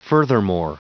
Prononciation du mot furthermore en anglais (fichier audio)
Prononciation du mot : furthermore